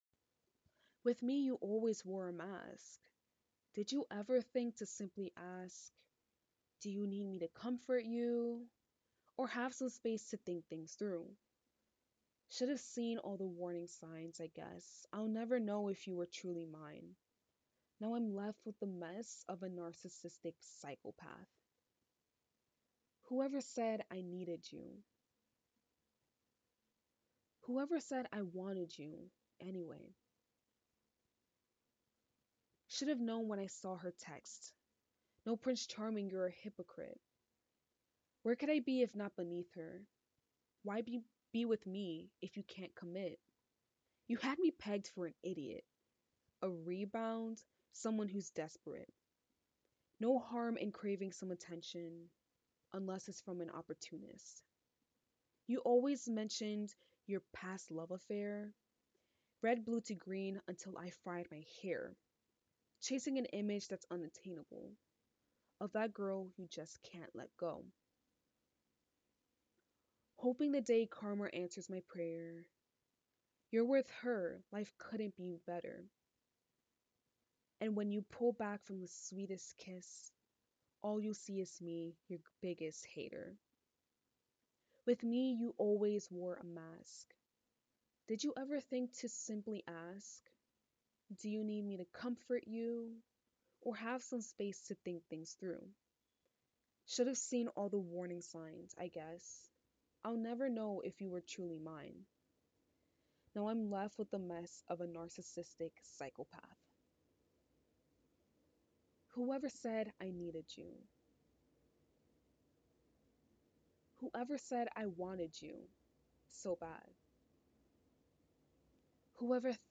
spoke word (demo)